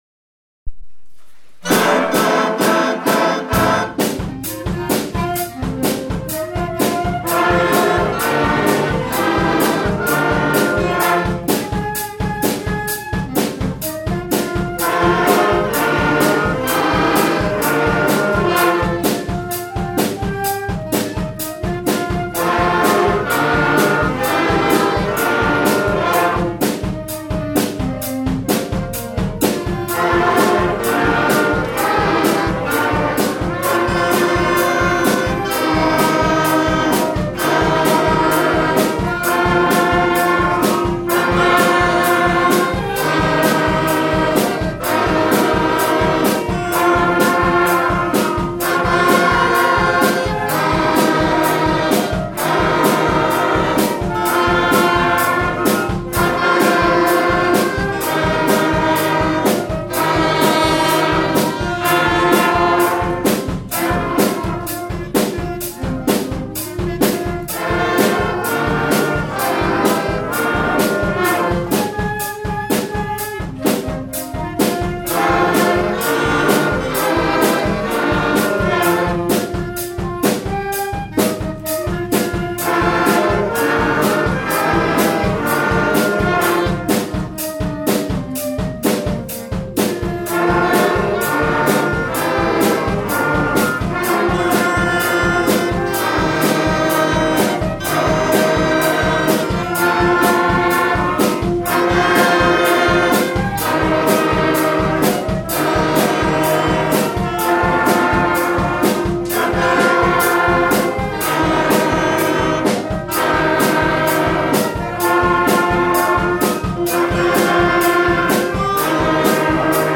Happy - primary wind day